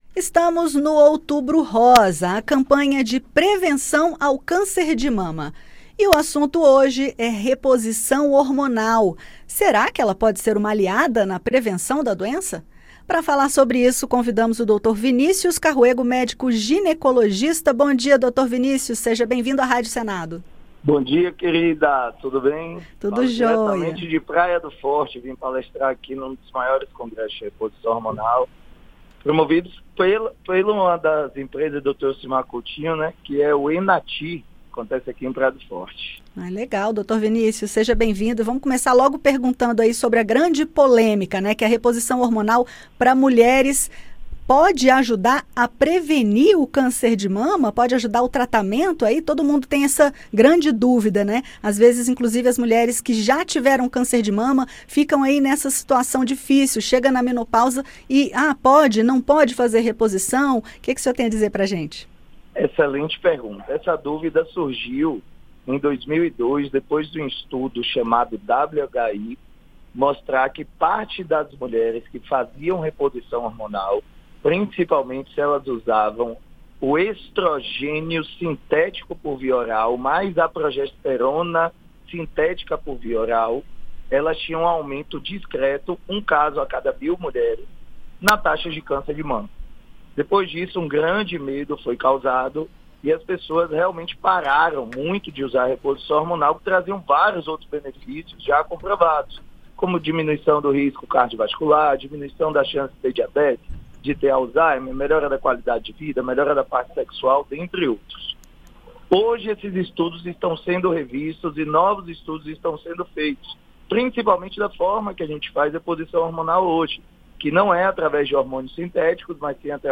entrevistou